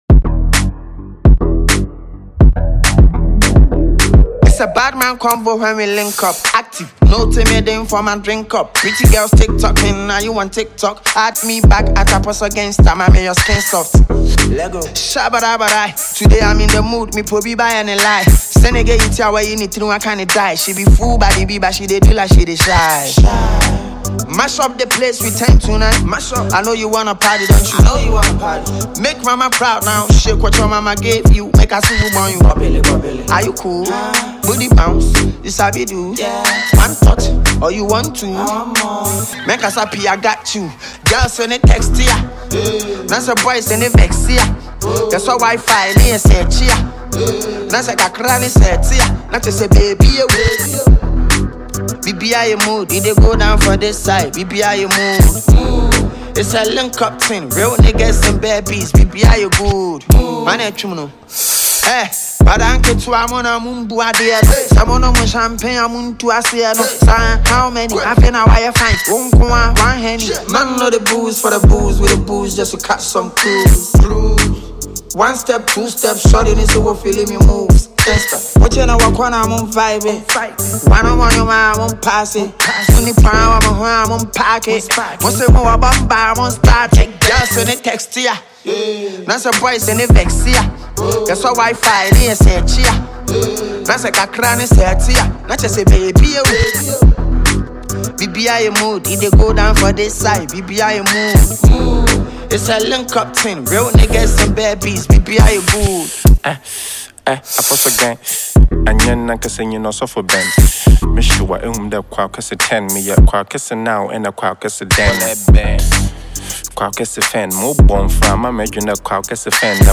Ghana Music Music
catchy song